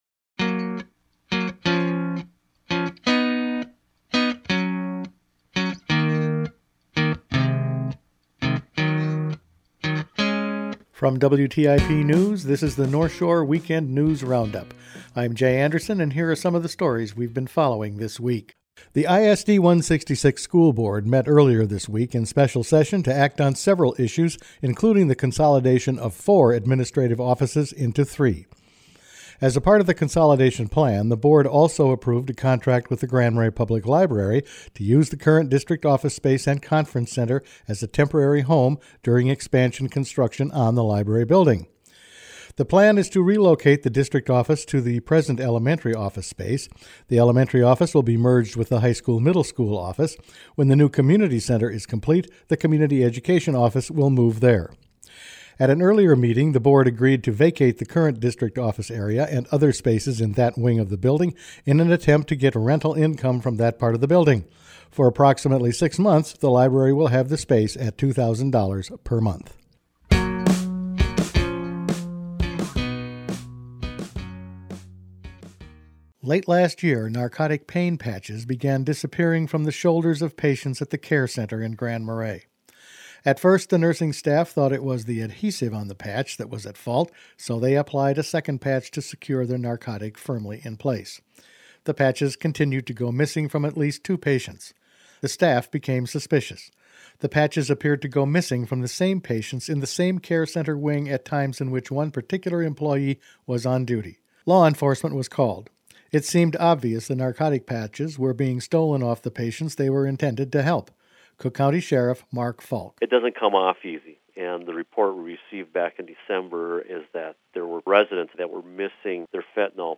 Each weekend WTIP news produces a roundup of the news stories they’ve been following this week. Office consolidations at ISD166, narcotics theft at the care center, scouts on the trails and the latest closures in Duluth for I-35 all in this week’s news.